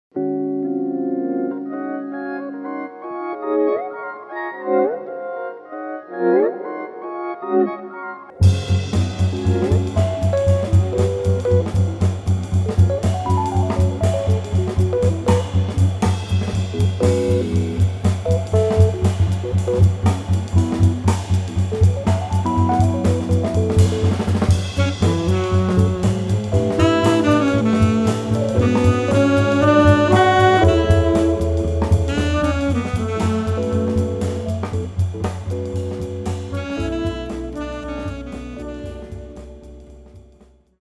sax
guitar
Fender Rhodes
bass
drums